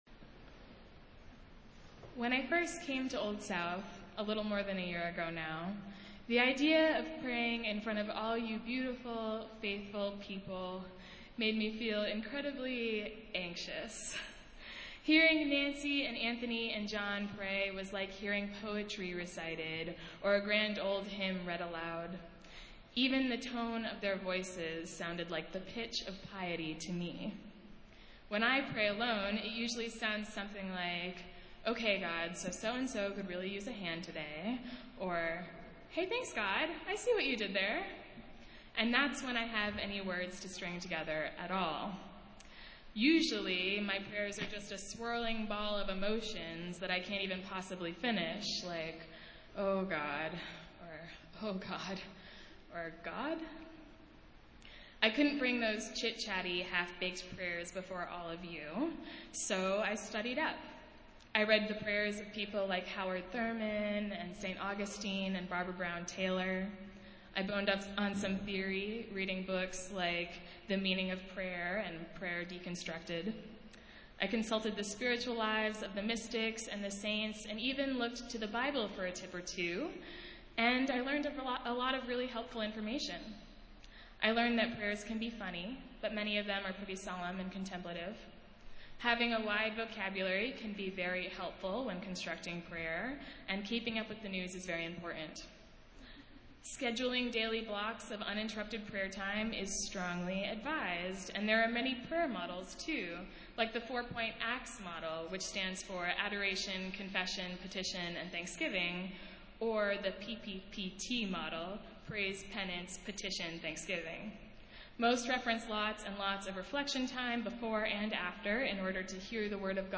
Festival Worship - Seventh Sunday after Pentecost